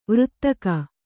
♪ vřttaka